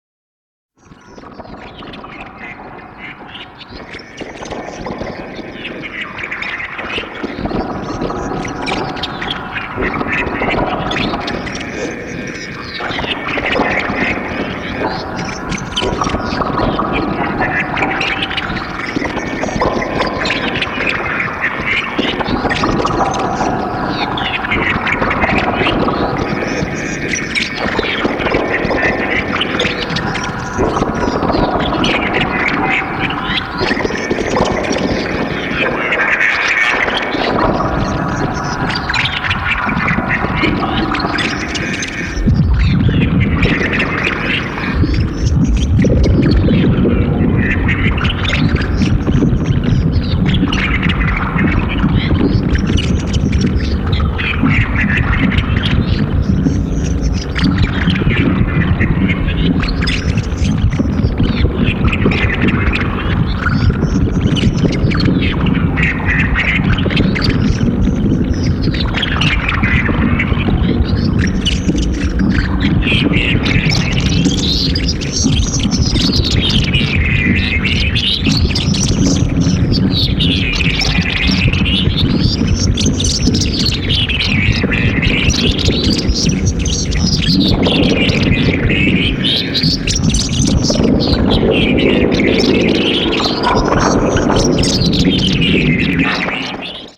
全編に渡って多種多様に何かが蠢いている様なこのノイズサウンドは一体何なんでしょう！？
今回もノイズ特有の中毒感は満載です～！